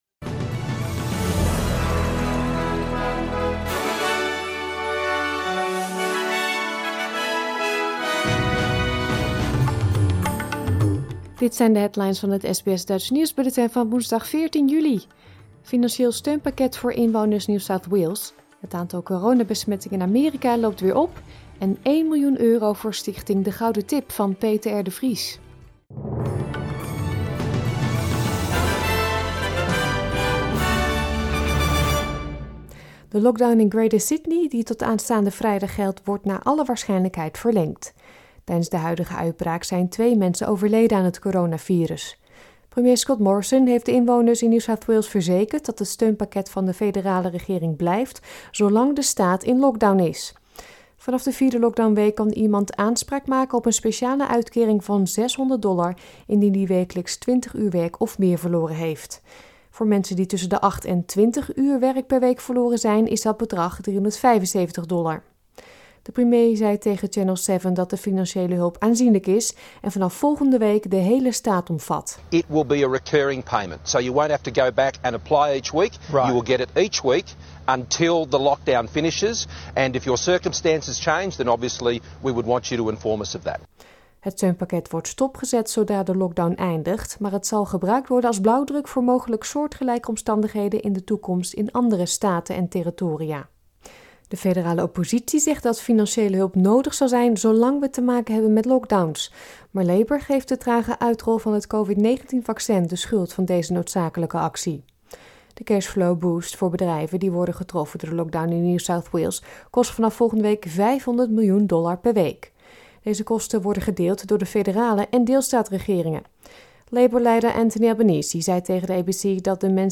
Nederlands/Australisch SBS Dutch nieuwsbulletin van woensdag 14 juli 2021